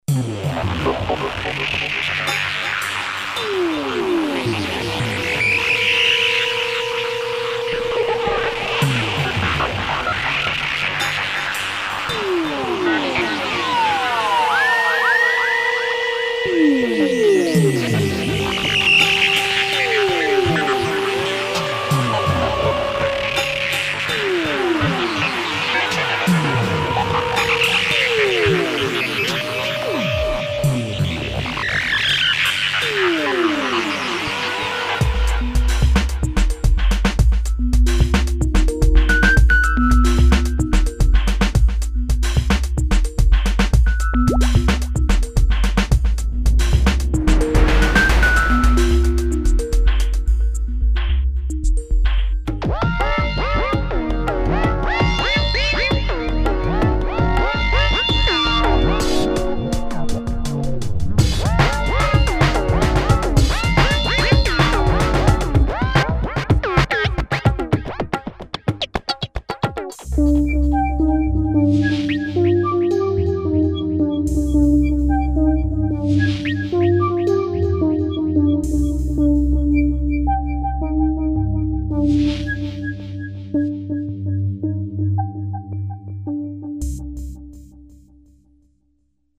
Mostly DJ-Live oriented in the LOOP FACTORY series grooveboxes based on AN analog physical modeling synthesis and sampled percussions.
demo frog lead
demo brass
demo techno pattern